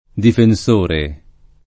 difenS1re] s. m. — lett. non com. difenditore [difendit1re]; nel femm., difenditrice lett., difensora popolare — Libro del difenditore della pace, tit. del volgarizzam. fior. (1363) del Defensor pacis